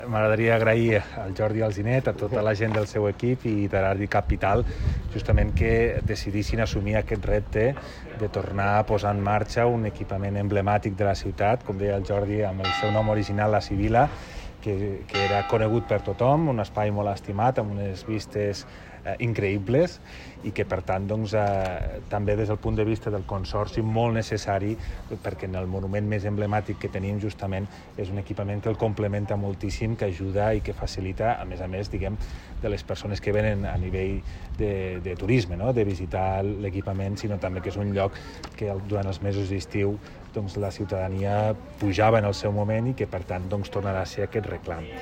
tall-de-veu-de-lalcalde-accidental-toni-postius-sobre-la-reobertura-del-servei-de-bar-al-turo-de-la-seu